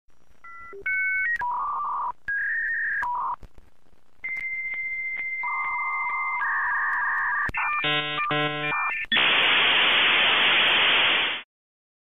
Dial Up
dial-up-internet-sound-effect-hd.mp3